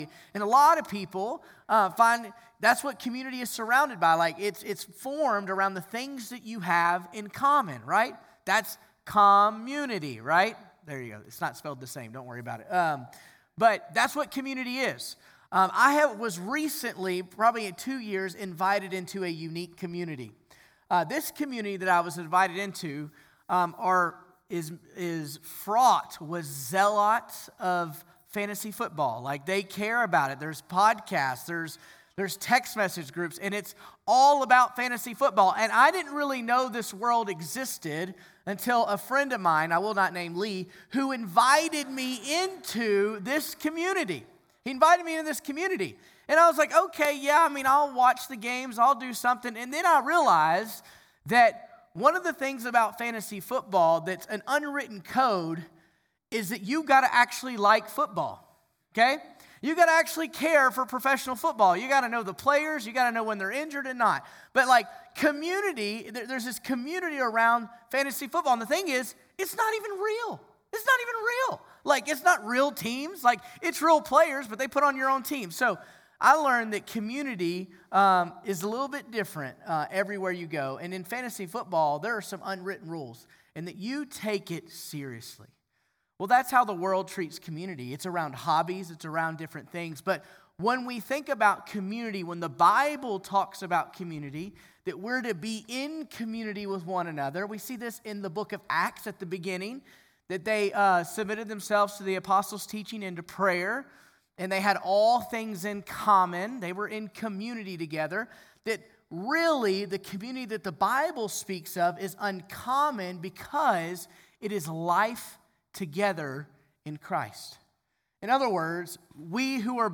September22Sermon.mp3